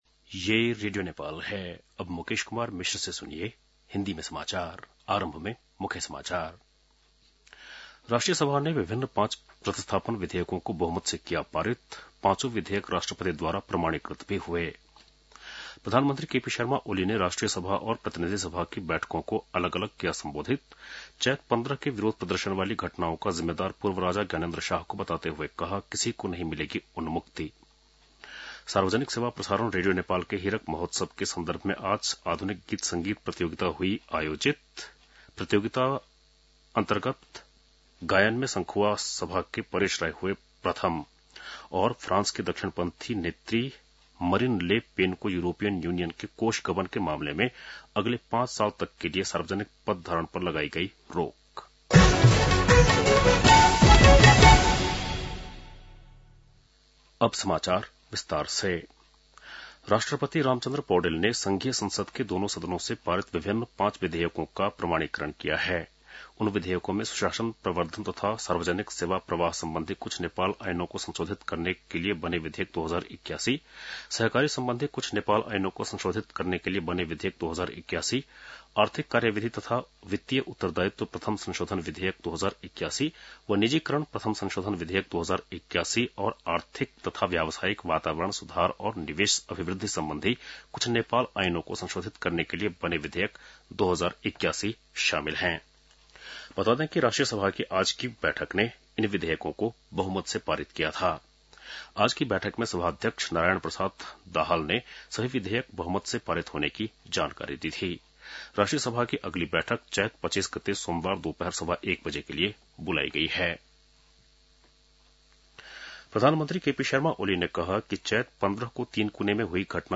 बेलुकी १० बजेको हिन्दी समाचार : १८ चैत , २०८१
10-pm-hindi-news.mp3